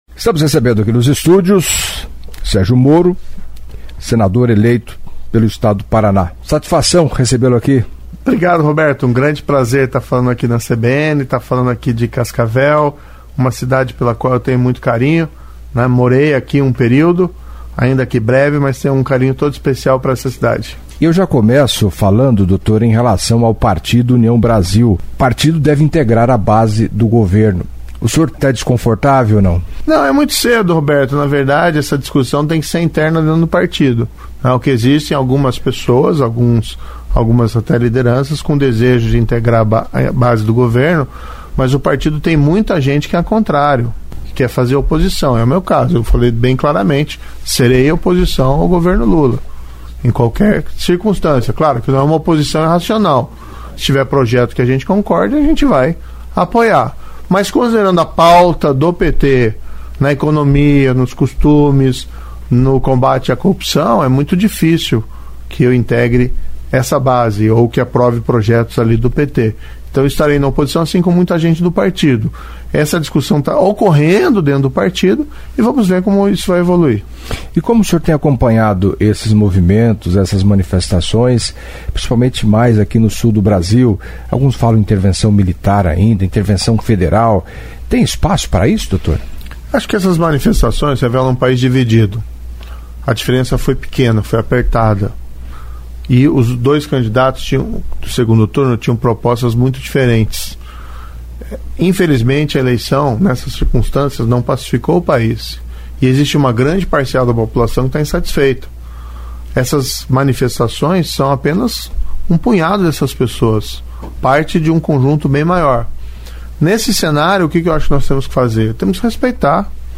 Em entrevista à CBN Cascavel nesta quarta-feira (30) Sérgio Moro, senador eleito pelo Paraná, falou da posição do seu partido, o União Brasil, de integrar a base do governo Lula, do STF que marcou julgamento que pode liberar Sérgio Cabral, dos movimentos realizados por brasileiros descontentes com o resultado da eleição presidencial.